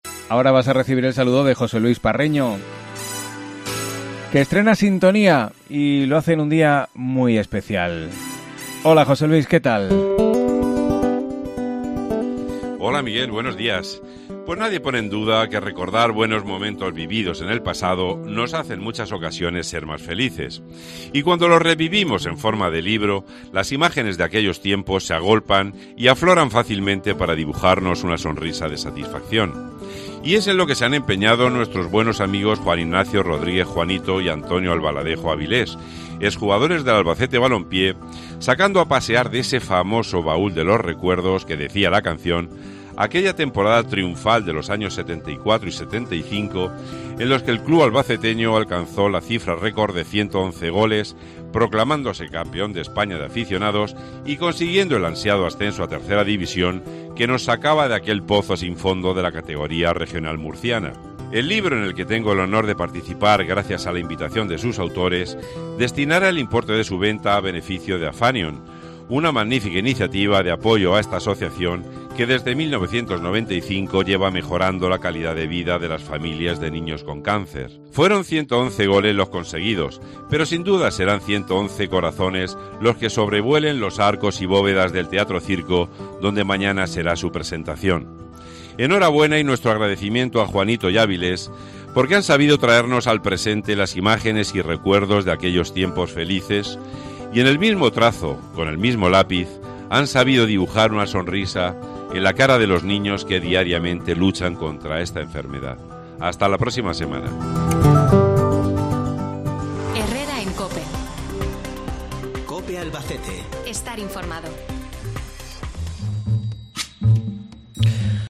columna radiofónica